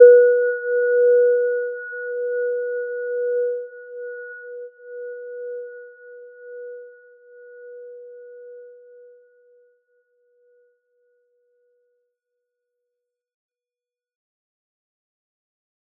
Gentle-Metallic-1-B4-p.wav